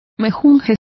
Complete with pronunciation of the translation of concoction.